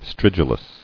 [strid·u·lous]